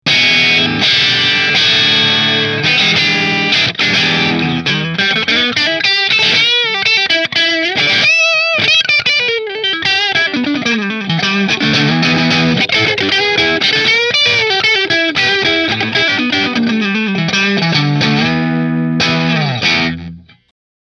It comes with Seymour Duncan Jazz and Custom Custom pickups, as well as a Hexaphonic RCM acoustic system and a built in synth.
Godin LGX Middle Through Marshall